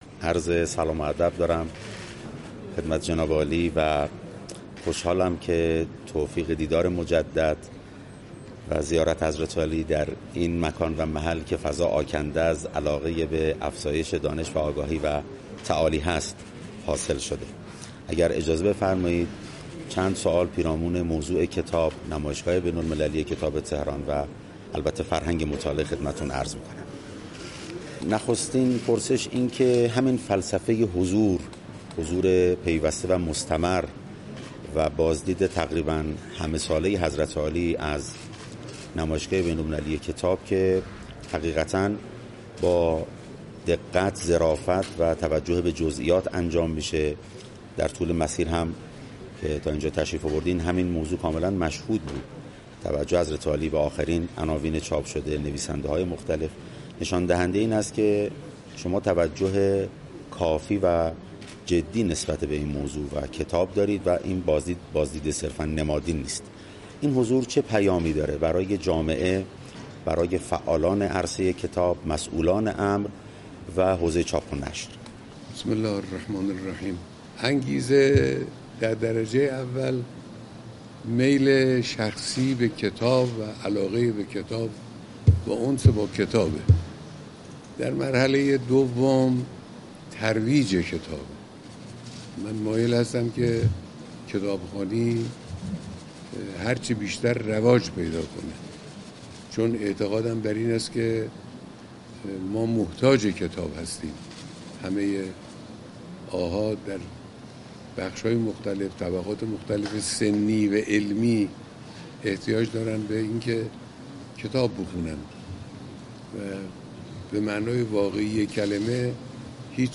رهبر انقلاب در جریان بازدید از نمایشگاه کتاب فرمودند:
حضرت آیت‌الله خامنه‌ای پس از بازدید از نمایشگاه کتاب در مصاحبه‌ای، انگیزه خود از چنین بازدیدی را در درجه اول میل شخصی و علاقه به کتاب برشمردند و افزودند: یکی دیگر از دلایل بازدید از نمایشگاه کتاب، زمینه‌سازی برای ترویج کتاب و کتابخوانی است.